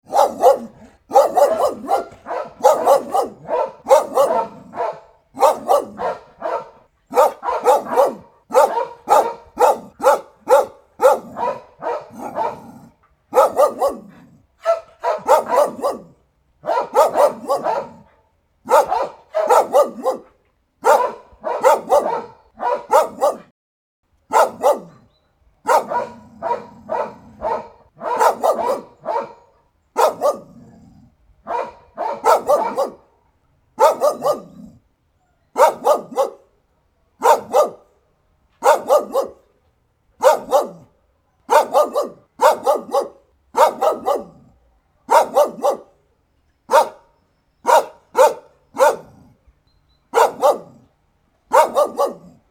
Two Big Dog Barking In City Park Sound Effect Download: Instant Soundboard Button